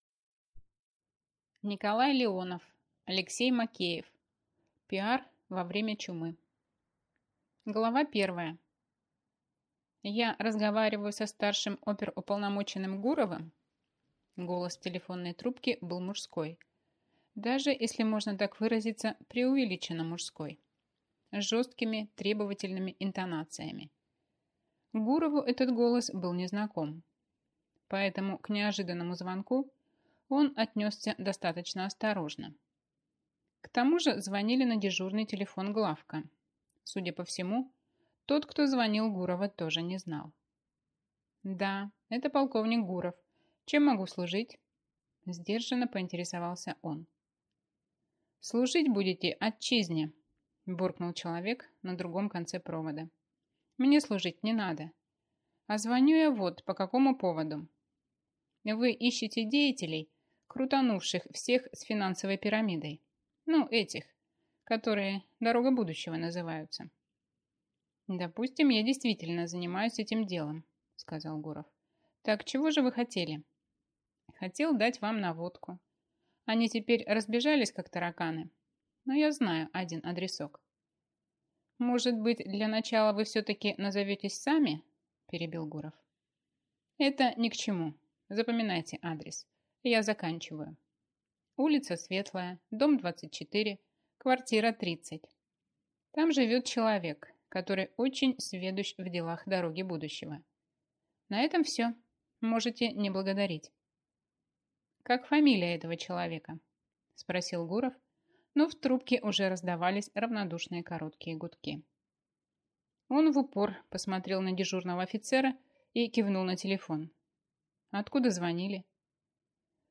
Аудиокнига Пиар во время чумы | Библиотека аудиокниг